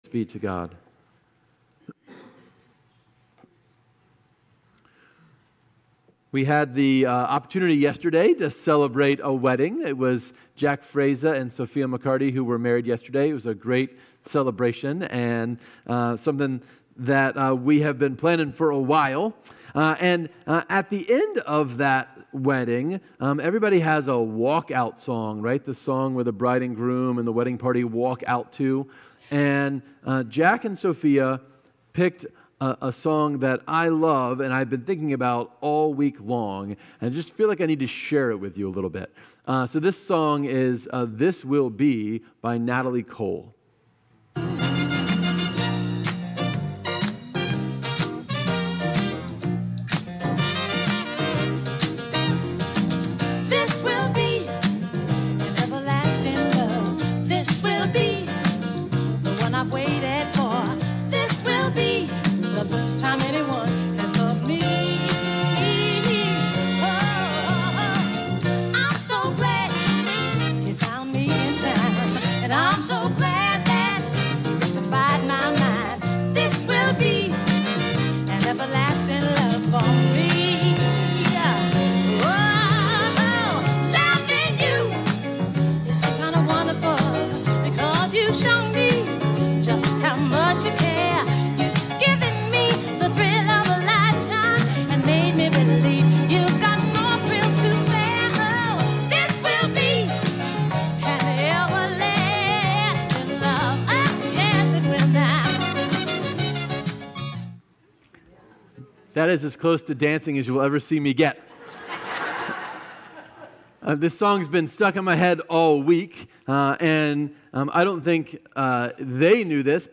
7-6-25+Sermon+Only.mp3